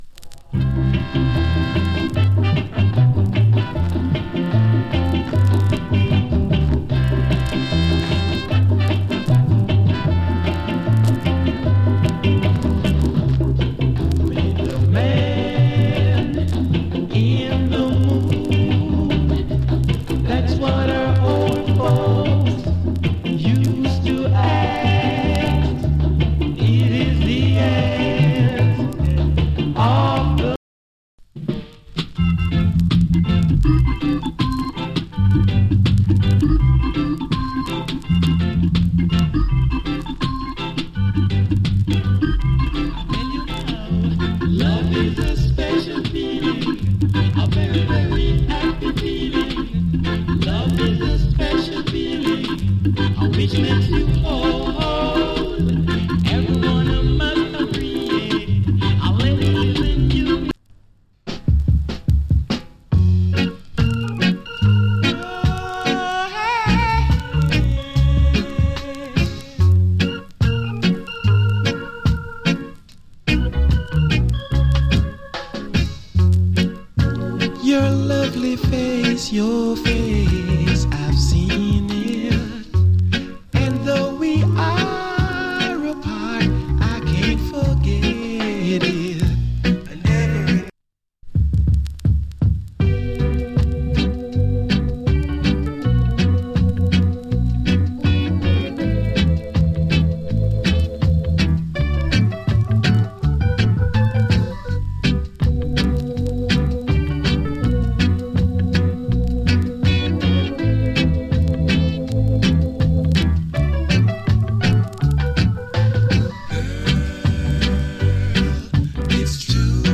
わずかにチリ、ジリノイズ有り。
70年代初期の EARLY REGGAE 〜 REGGAE 期の音源集 !!